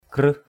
/krɯh/